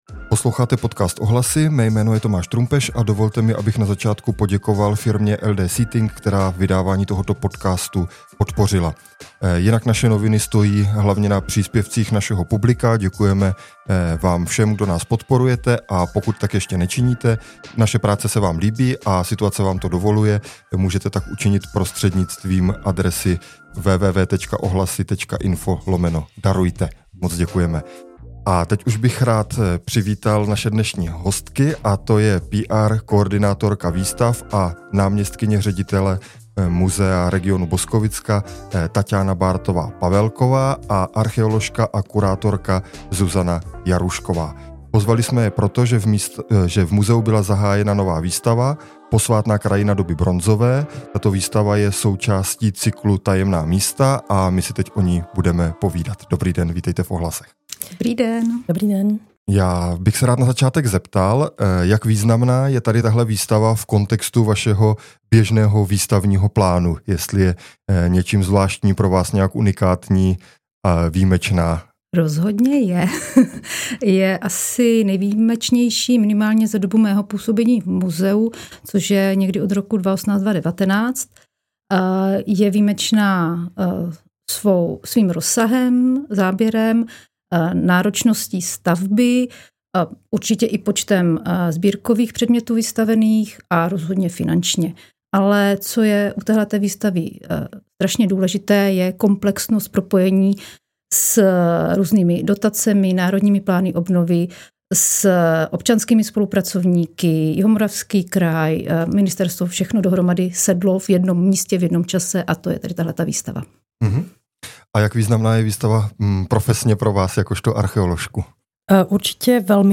Rozhovor o nové výstavě Posvátná krajina doby bronzové